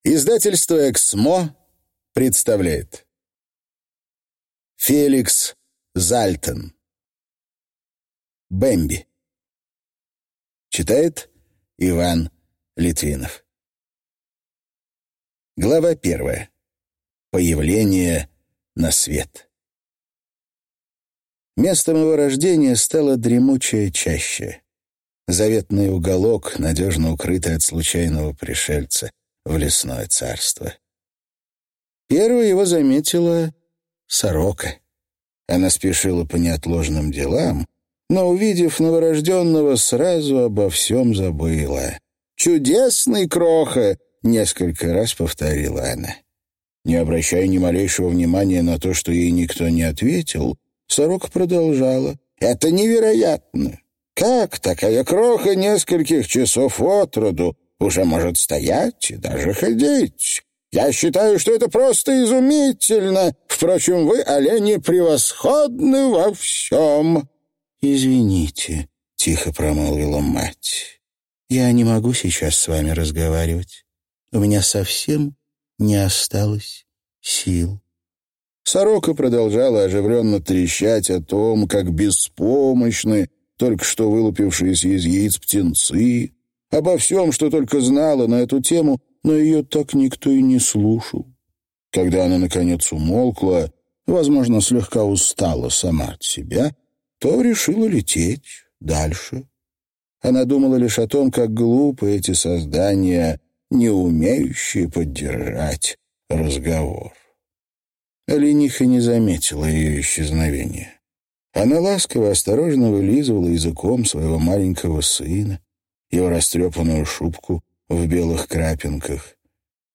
Аудиокнига Бемби | Библиотека аудиокниг